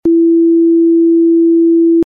sound  healing, vibration, brain wave frequency, cimatic  therapy
333 Hz (demo